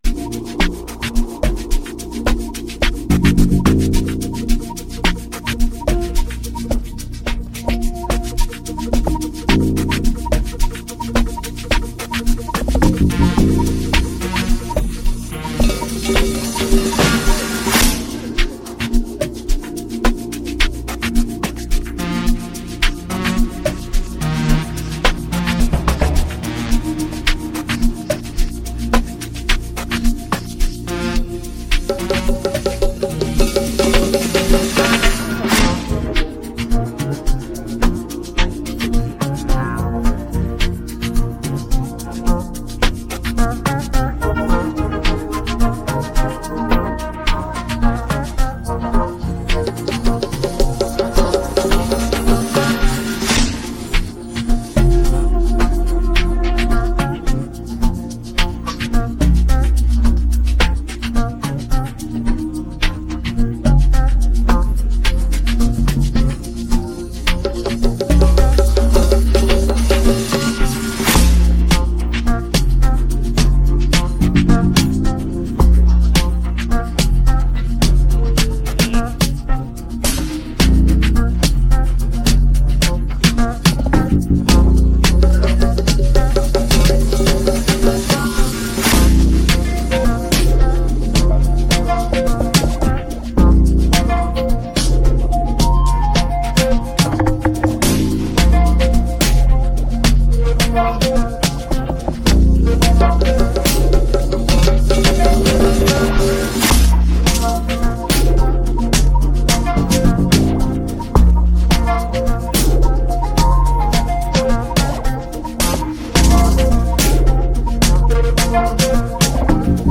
amapiano instrumental